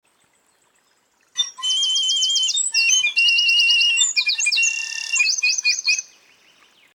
piccolo-and-wren.mp3